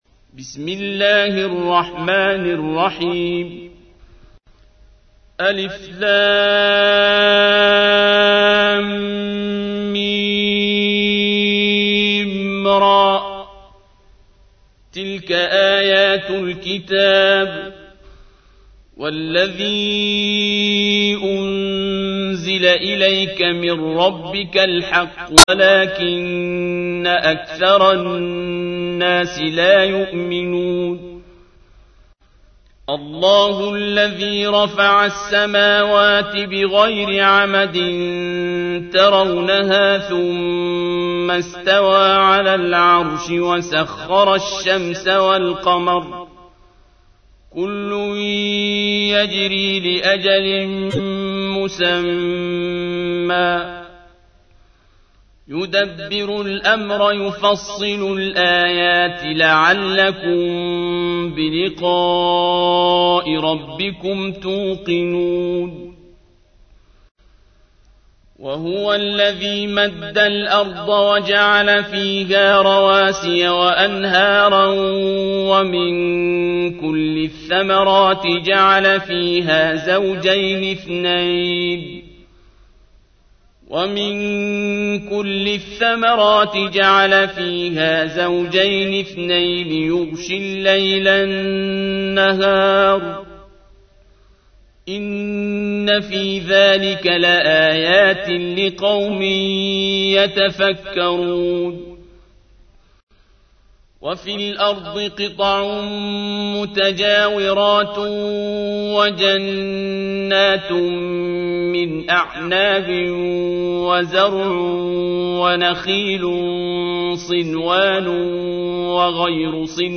تحميل : 13. سورة الرعد / القارئ عبد الباسط عبد الصمد / القرآن الكريم / موقع يا حسين